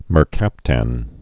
(mər-kăptăn)